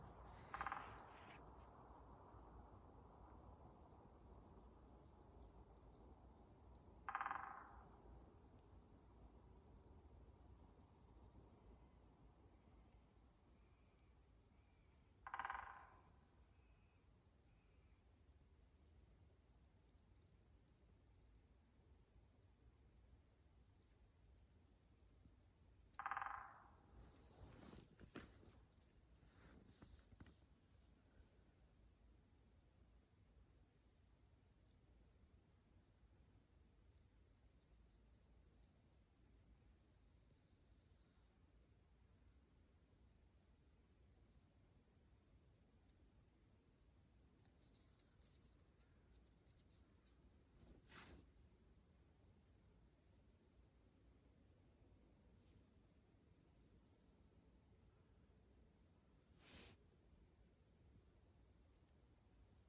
Great Spotted Woodpecker, Dendrocopos major
Ziņotāja saglabāts vietas nosaukumsGaujas iela/Mūrleja
NotesDižraibā dzeņa bungošana Cēsīs